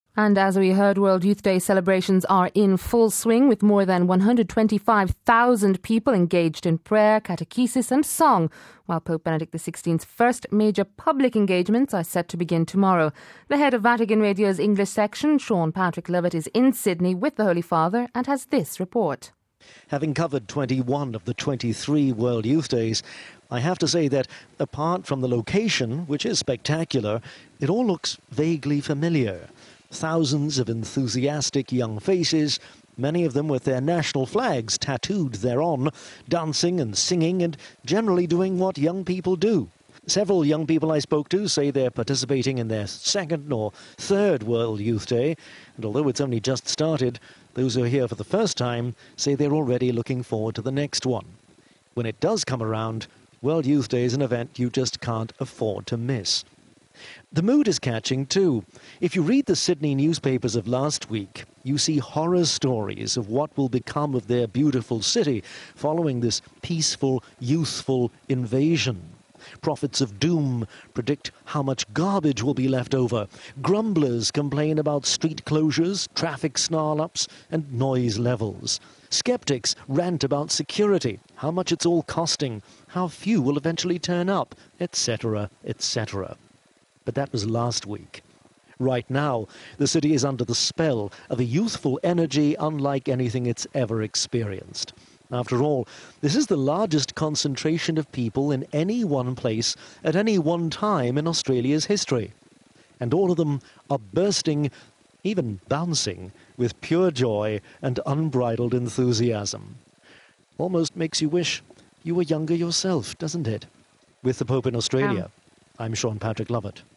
and has this report